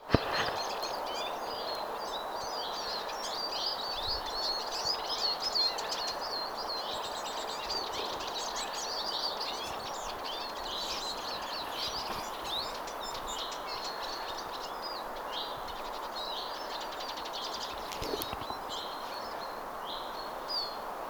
vihervarpuset laulavat kuorossa
vihervarpuset_laulavat_kuorossa.mp3